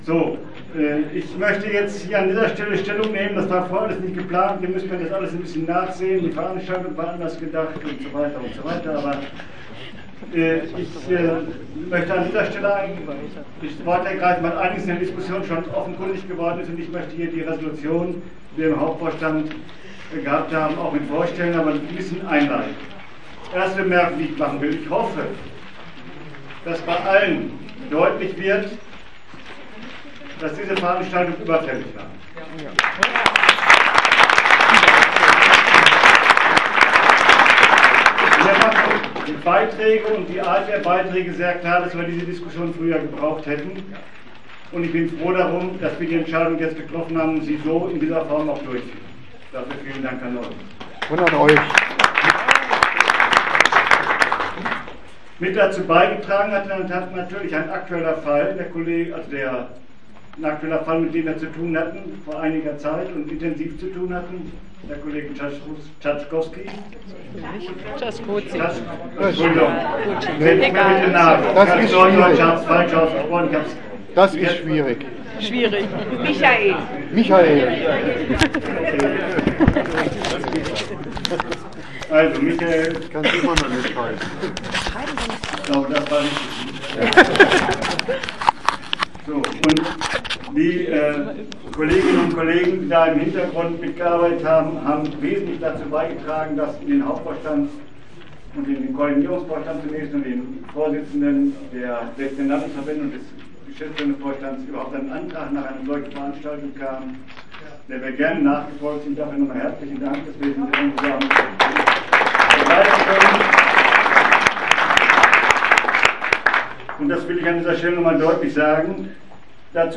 17. März 2012: Veranstaltung der Gewerkschaft Erziehung und Wissenschaft (GEW) zum Thema „40 Jahre Radikalenerlass“ Geschwister-Scholl-Gesamtschule Göttingen